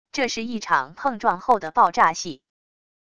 这是一场碰撞后的爆炸戏wav音频